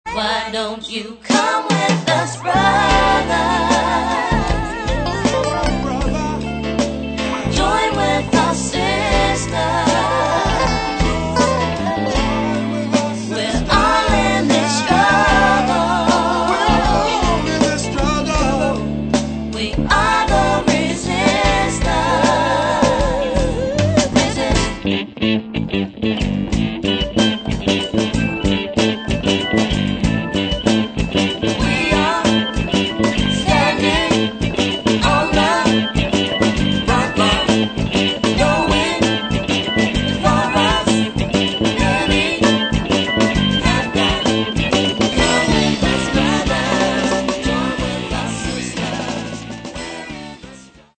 Hip Hop, Reggae, Funk, Latin Music, World Music
opens in a churchy gospel / R&B vein